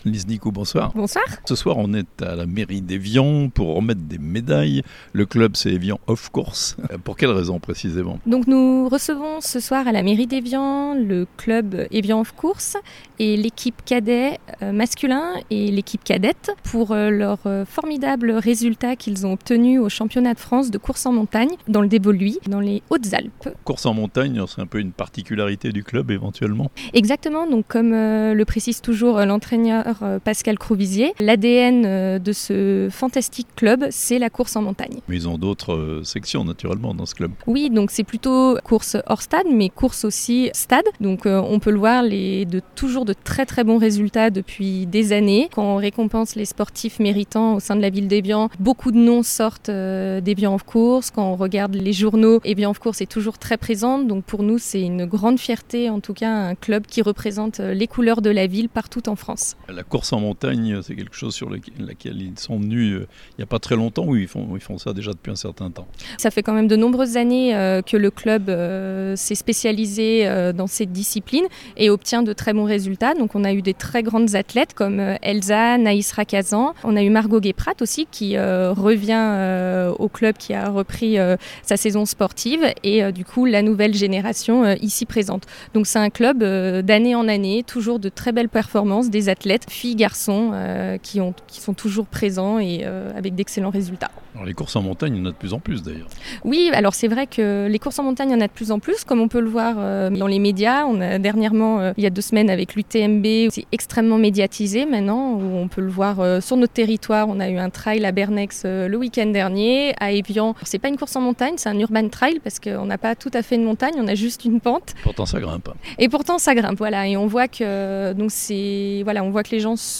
Evian : remise de médailles aux jeunes athlètes du club Evian Off Course (interview)